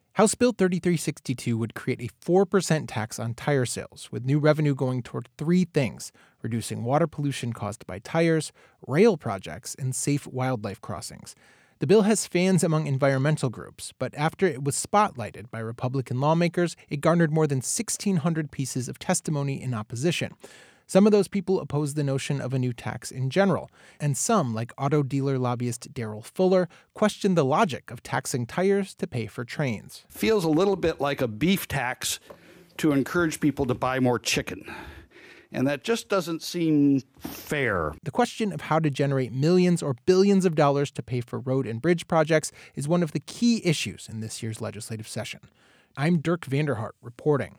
Local News